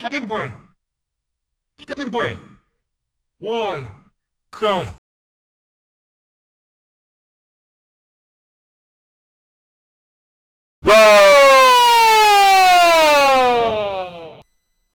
Royalty-free children-s-voices sound effects
kids-voice-saying-tally-h-6zkv5hbt.wav